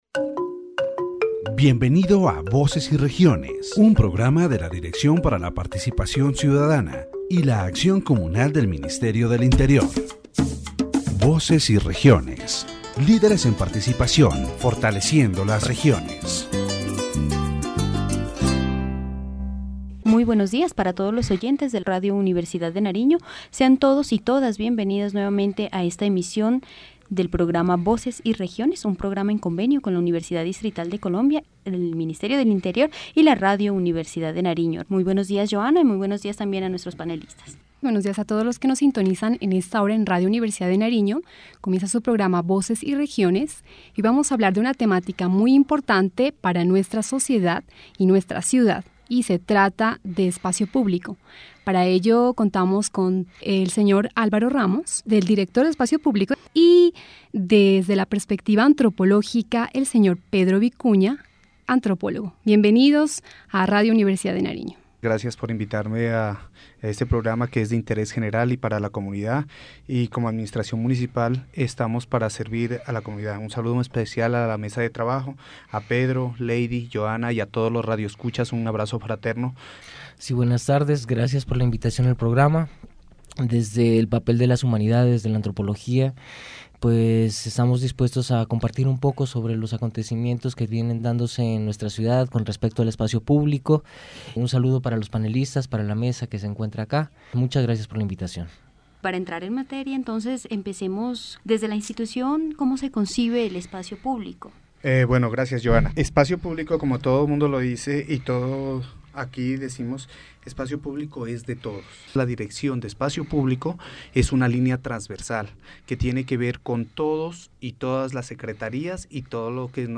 The radio program "Voices and Regions" addressed the issue of public space from various perspectives, including institutional, anthropological and social. Experts and local authorities participated to analyze the importance of public space in the city of Pasto and the challenges it faces.